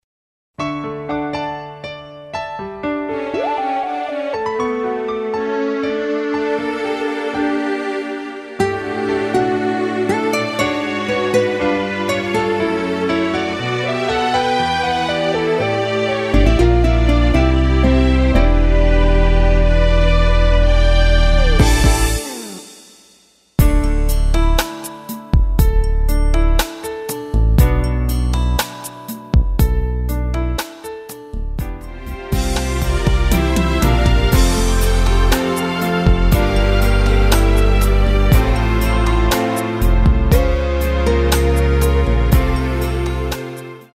원곡의 보컬 목소리를 MR에 약하게 넣어서 제작한 MR이며